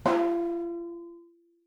healing-soundscapes/Sound Banks/HSS_OP_Pack/Percussion/temp/vibraring_v1_rr1.wav at cc6ab30615e60d4e43e538d957f445ea33b7fdfc
vibraring_v1_rr1.wav